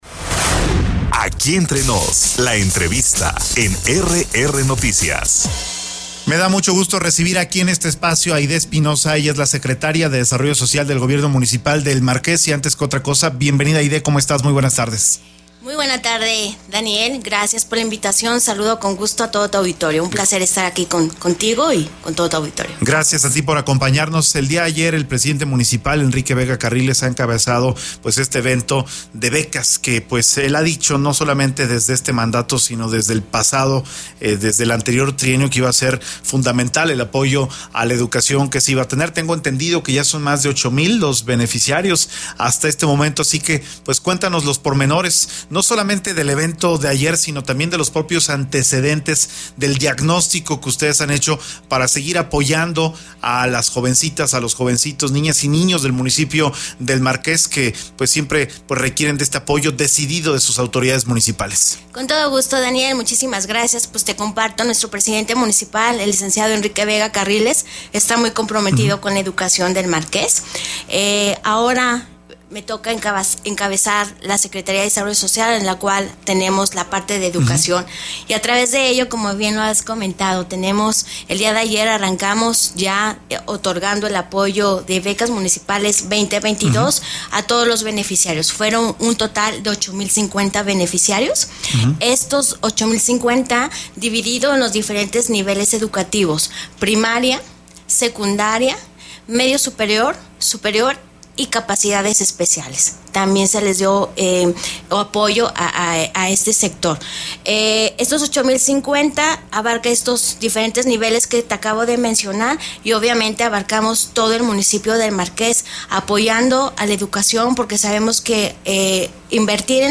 EntrevistasOpiniónPodcast